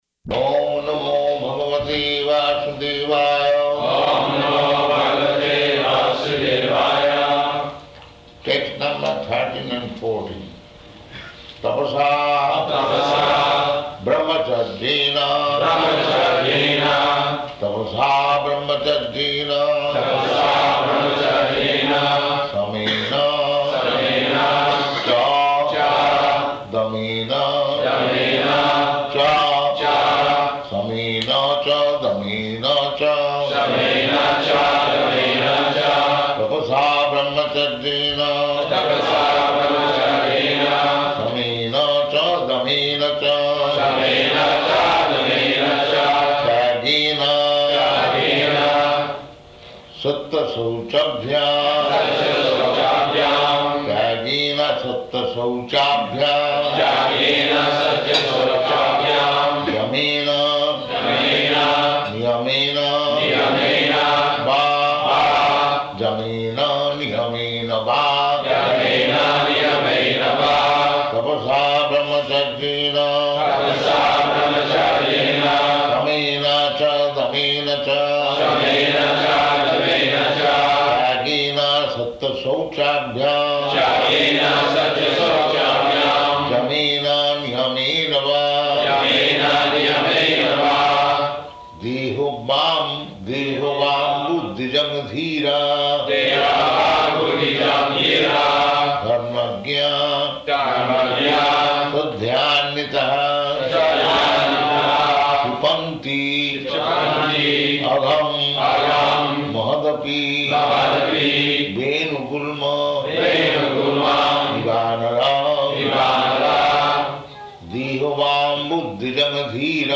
Śrīmad-Bhāgavatam 6.1.13-14 --:-- --:-- Type: Srimad-Bhagavatam Dated: May 14th 1976 Location: Honolulu Audio file: 760514SB.HON.mp3 Prabhupāda: Oṁ namo bhagavate vāsudevāya.